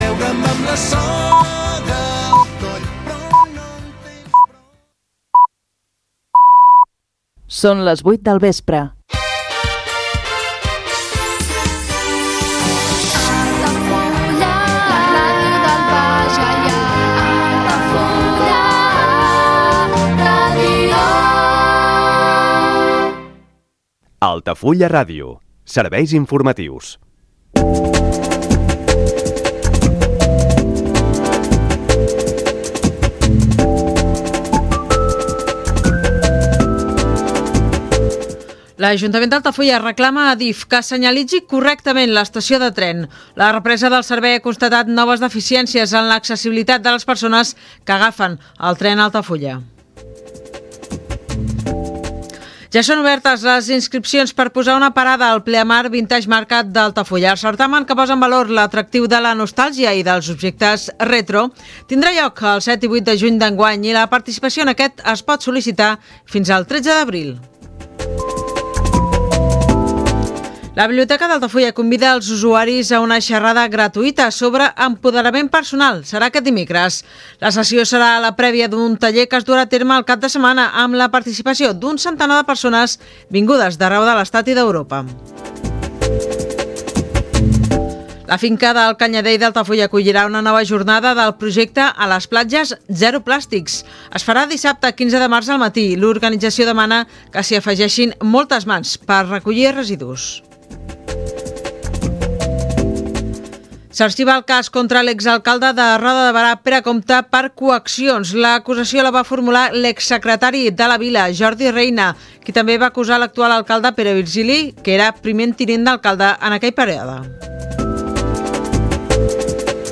Programa de cinema clàssic produït per Altafulla Ràdio. La música de cinema és el fil conductor de l’espai